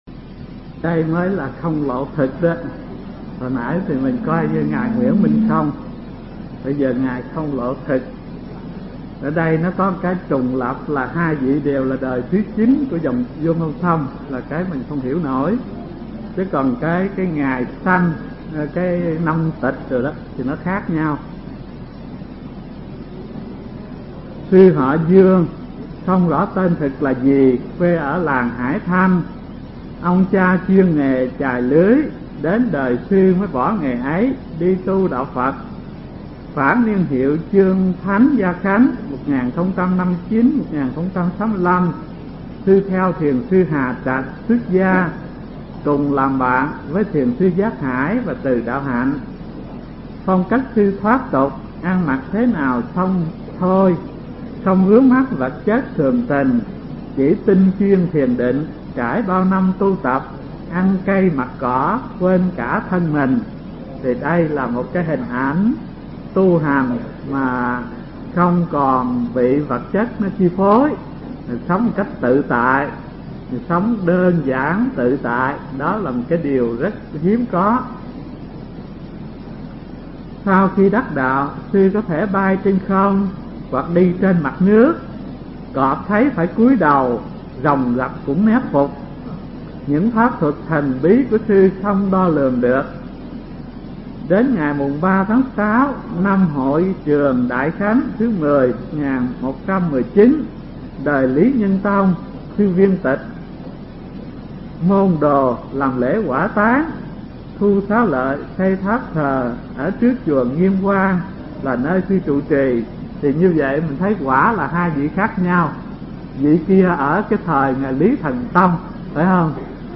Mp3 Pháp Âm Thiền Học Phật Giáo Việt Nam 86 – Không Lộ (Họ Dương) (Đời 9 Dòng Vô Ngôn Thông) – Hòa Thượng Thích Thanh Từ giảng tại trường Cao Cấp Phật Học Vạn Hạnh, từ năm 1989 đến năm 1991